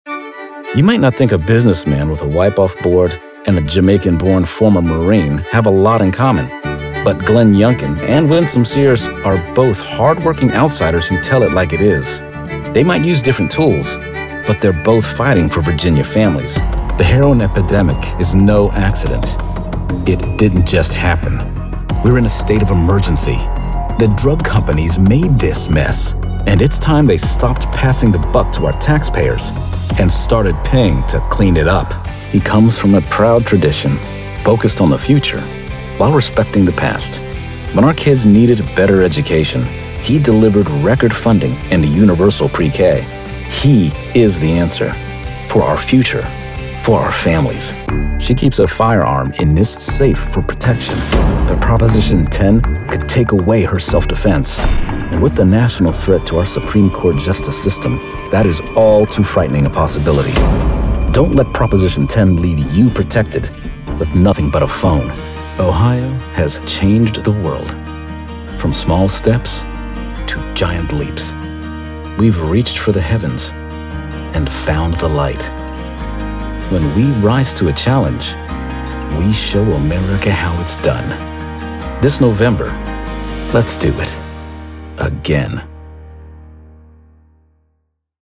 Narrator with a strong, confident and friendly voice to tell your story.
Potitical Demo